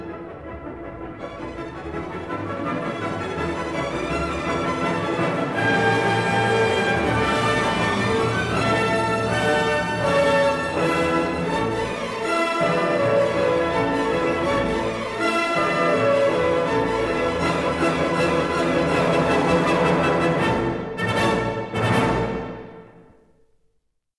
Stereo recording made in April 1961 at
Studio No.1, Abbey Road, London